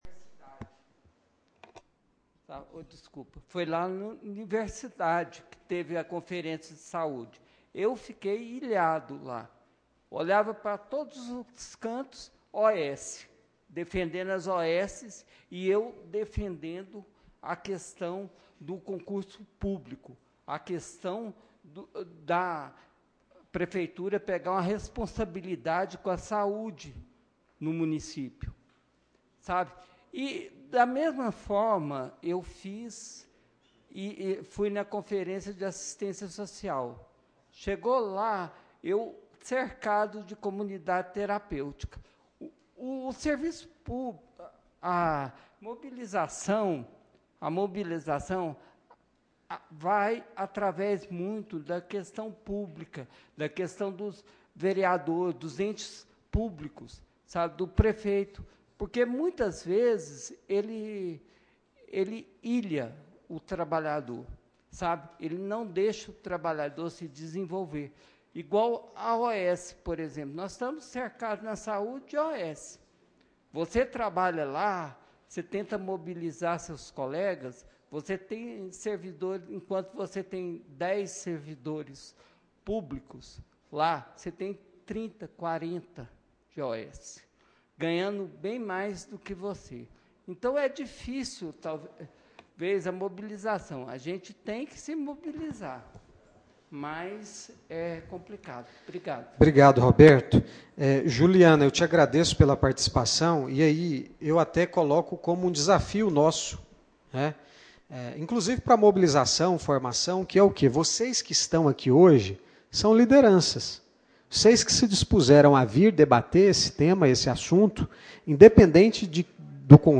Audiência Pública Com Servidores Públicos . Dia 10/03/2025.